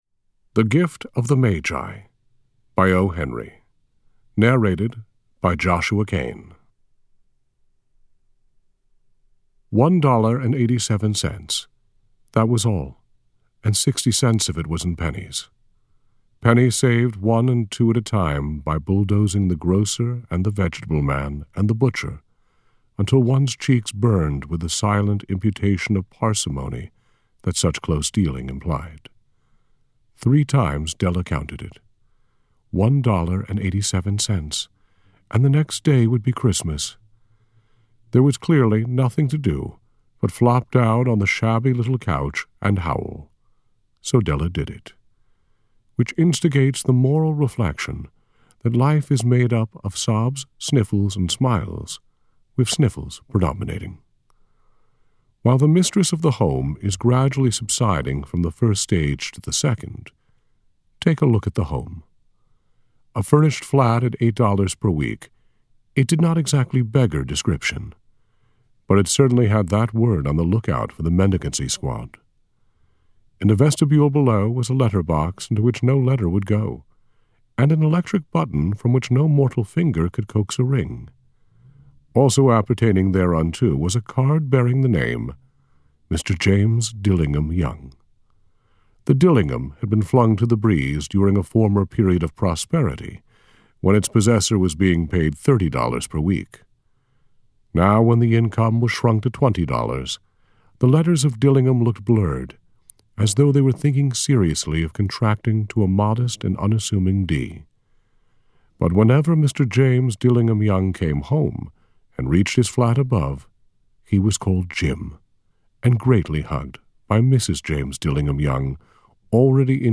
This Recording of O’Henry’s The Gift of the Magi was recorded during the onset of a hurricane several years ago and was completed and loaded up moments before the lights went out!